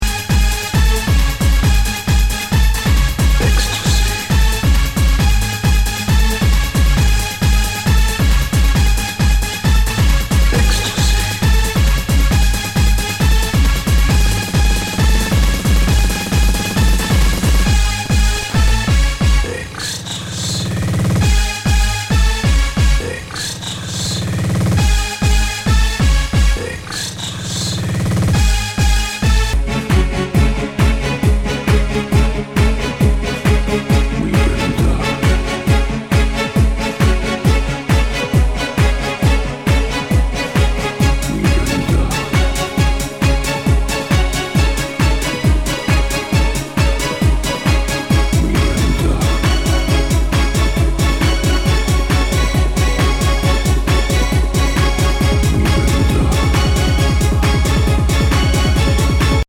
HOUSE/TECHNO/ELECTRO
ナイス！ハード・ハウス！